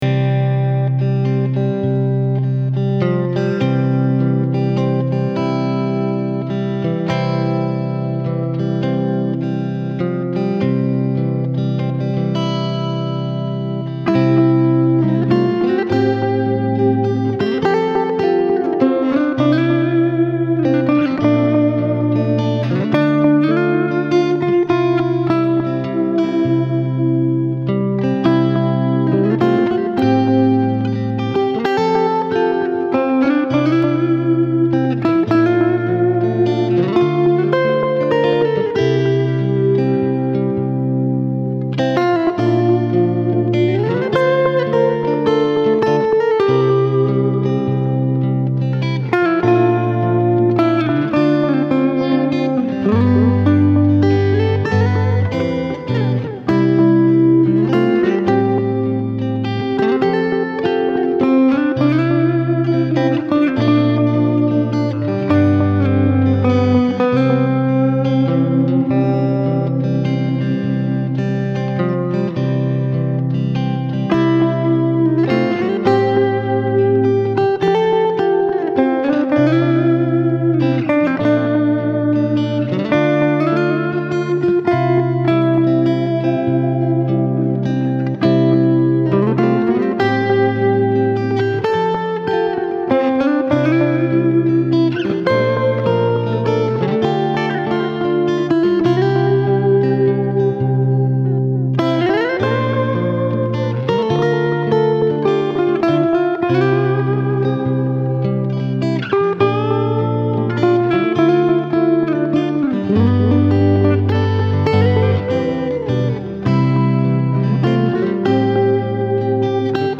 I was thinking of a blues waltz that popped into my head, and wanted to track it so I wouldn’t forget.
But as I played through the melody I had in mind, it sounded so good with just a finger-picked guitar that I decided to forgo the lyrics altogether.
Mind you, I tried the song with three different guitars before I found the one with just the right chime.